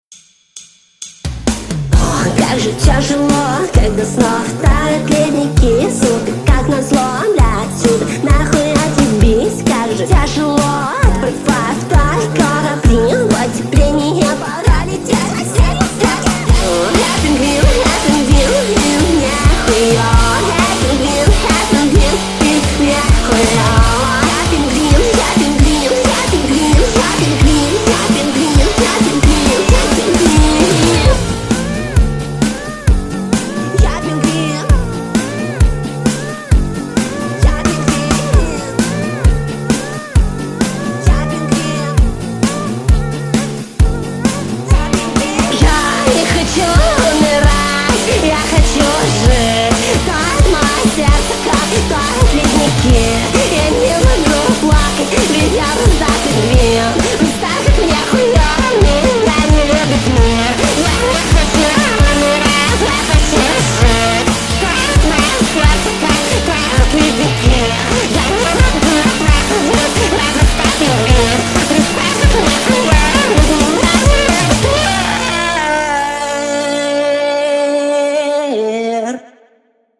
Penguin (punk rock
Написал какой-то странный рок O_o (если чо с телефона вокал записан, бит вейвом) Как по мне вышло даже очень не плохо, но не нравится дисторшн с ударом в потолок.